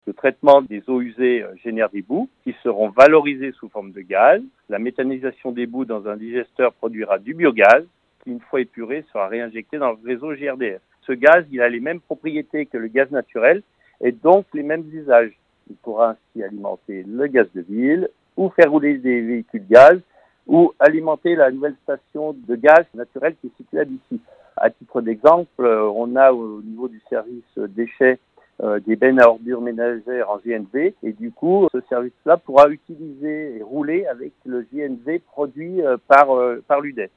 Les explications de Daniel Rochaix, il est Vice-président de Grand-Chambéry en charge de l’eau, de l’assainissement et des eaux pluviales.